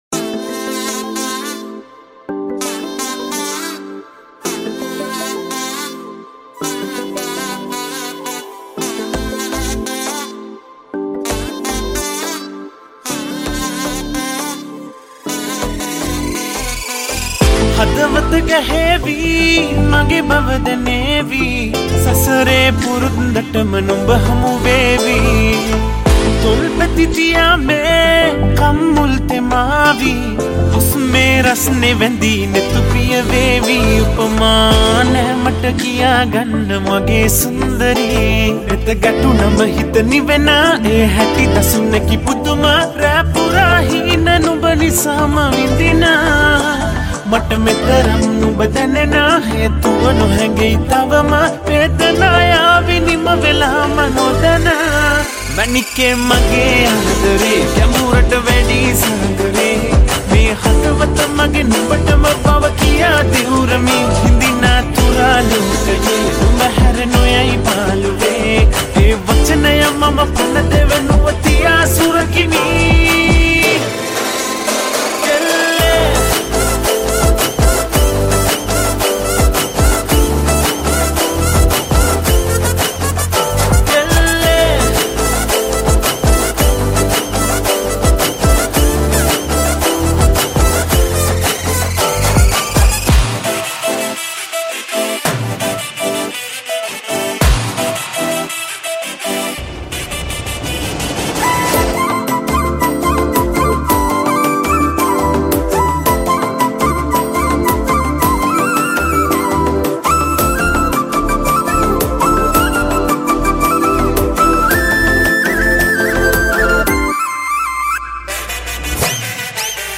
Vocal
Mixed & Mastered